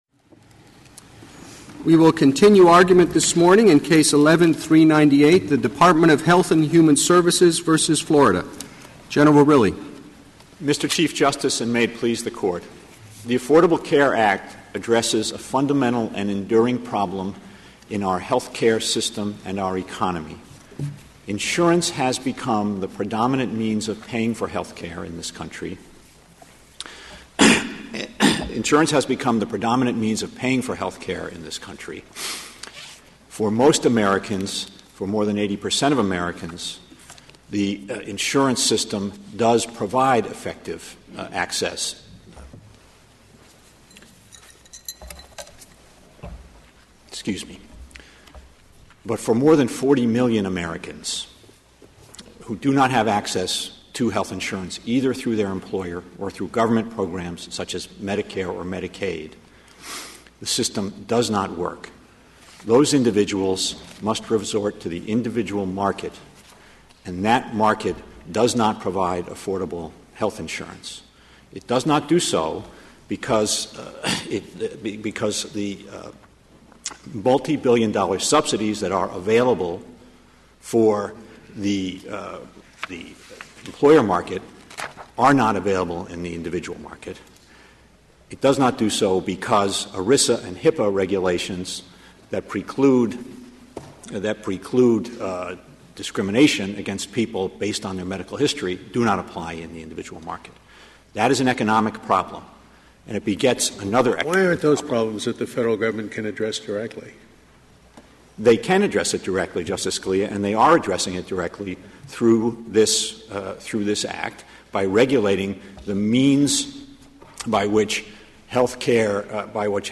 The Supreme Court on Tuesday heard the second of three days of oral arguments on the fate of President Obama's health care law.